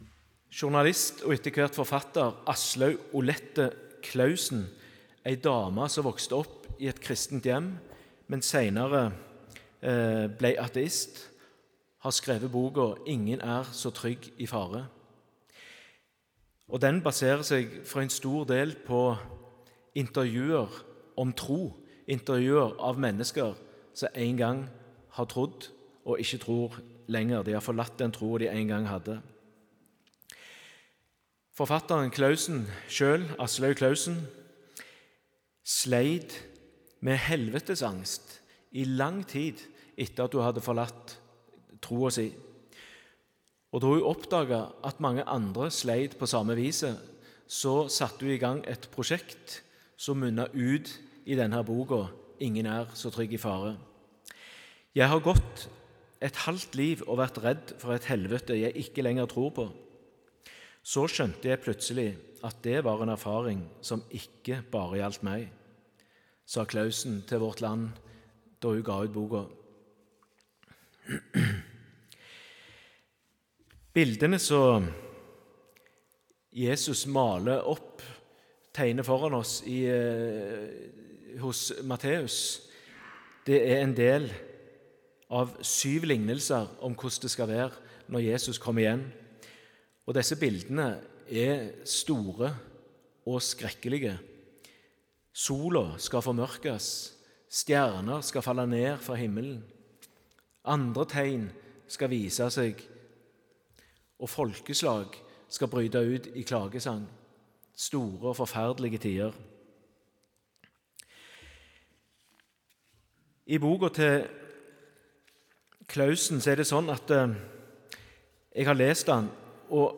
Utdrag fra talen (Hør hele talen HER )